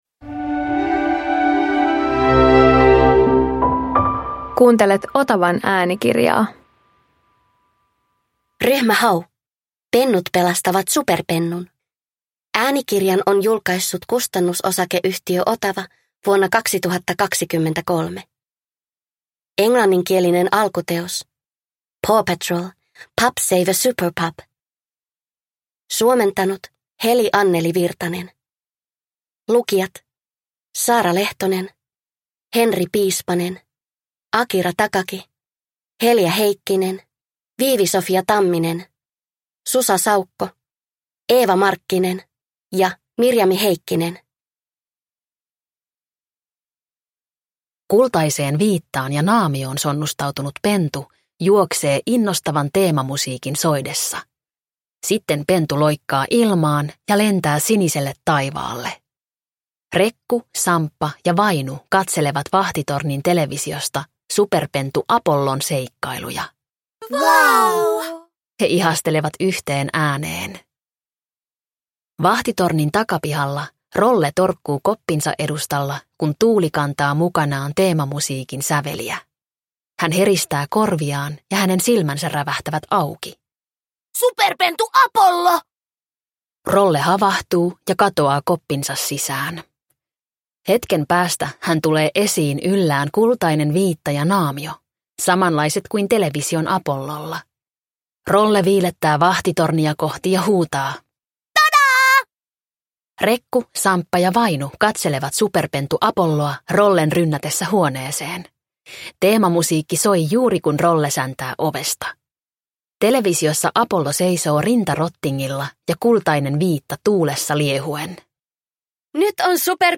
Ryhmä Hau - Pennut pelastavat superpennun – Ljudbok